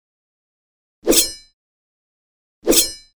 Звуки фехтования
Звук вынимания шпаги из ножен